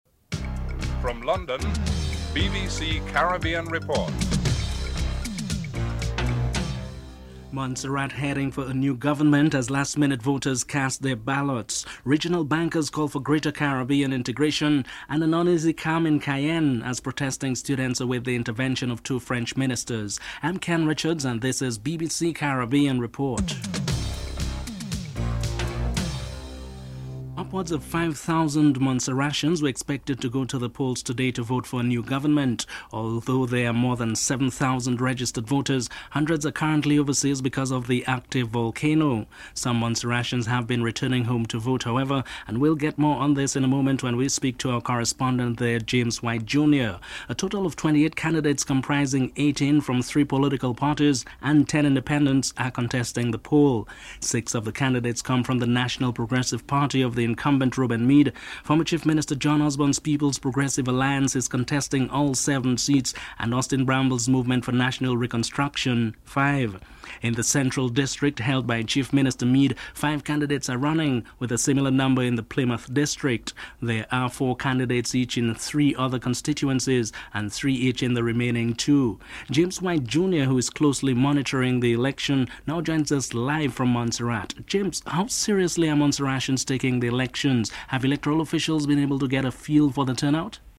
The British Broadcasting Corporation
1. Headlines (00:00-00:27)